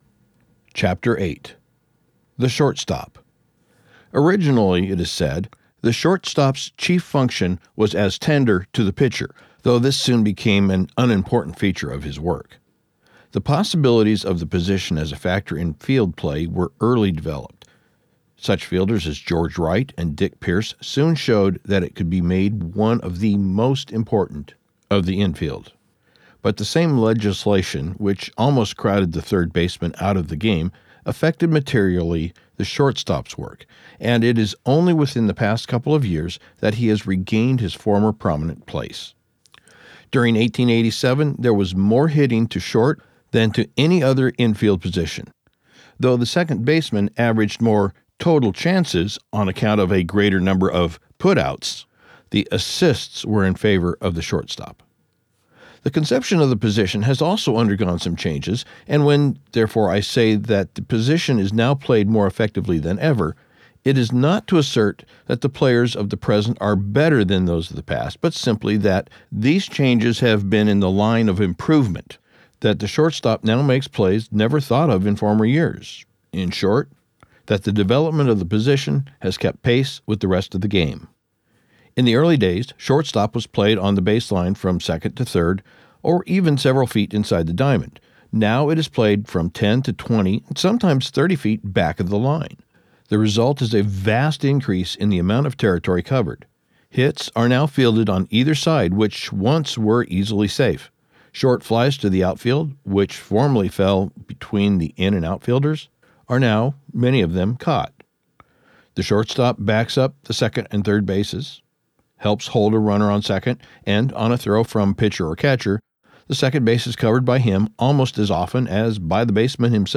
Audiobook production Copyright 2025, by GreatLand Media